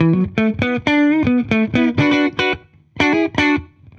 Index of /musicradar/dusty-funk-samples/Guitar/120bpm
DF_70sStrat_120-B.wav